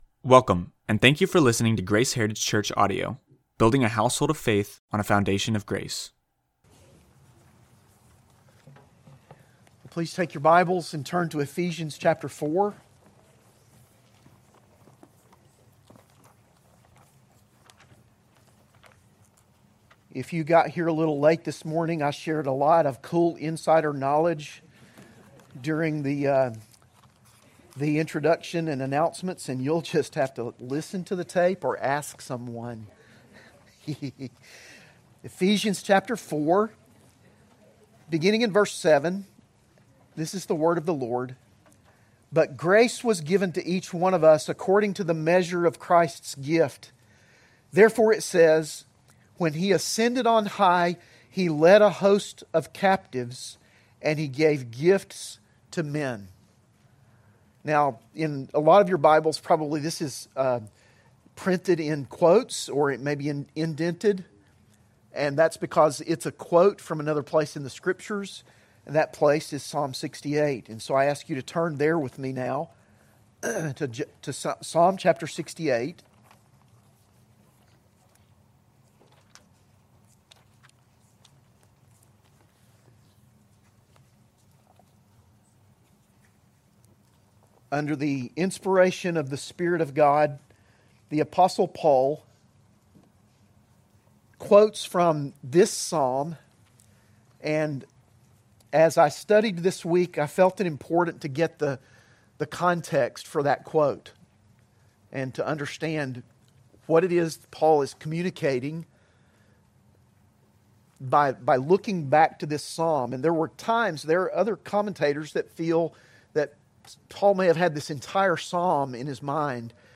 AM Worship